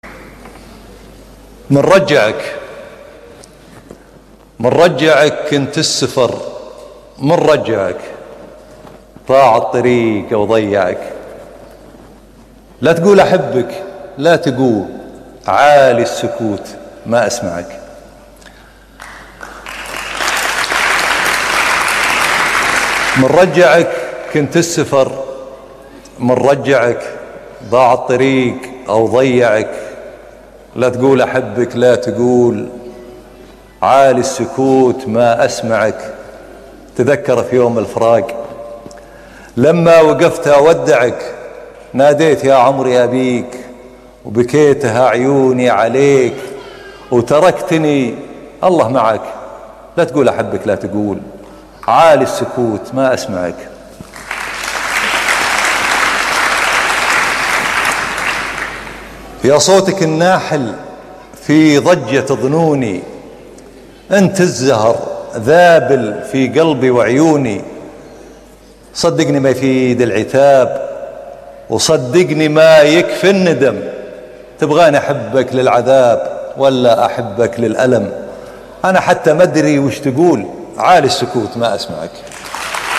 في صوتها ناي !
* جميع القصائد من أمسية القاهرة 2009